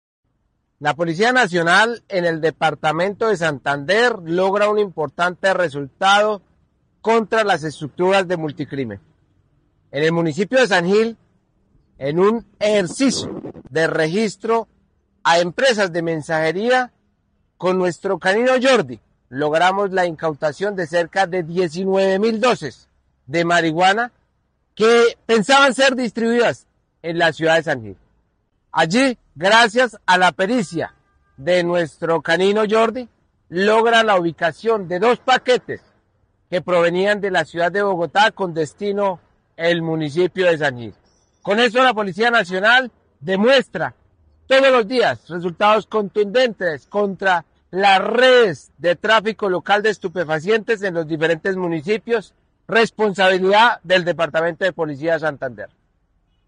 Coronel Néstor Arévalo, Cmdt. del Departamento de Policía Santander